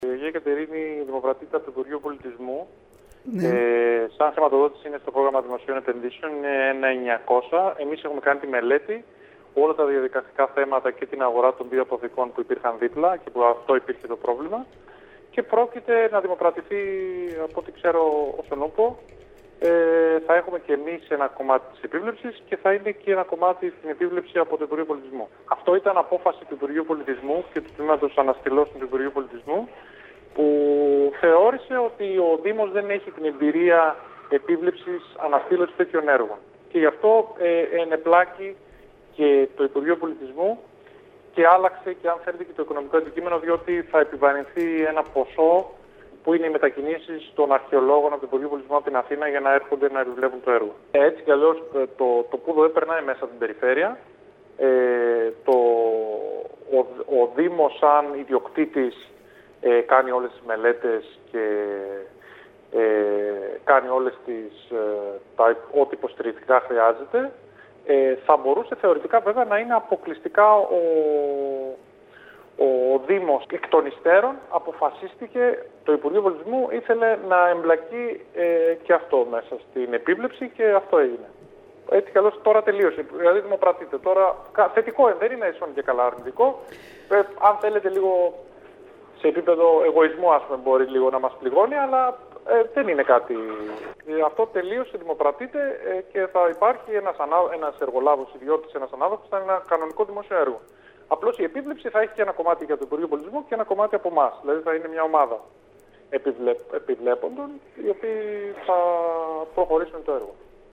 Μιλώντας στην ΕΡΤ Κέρκυρας ο αντιδήμαρχος τεχνικών υπηρεσιών Αν. Γουλής υπογράμμισε ότι πρόκειται για μια απόφαση που πλήττει το γόητρο του δήμου αλλά δεν πρόκειται να δημιουργήσει προβλήματα στην εξέλιξη του έργου.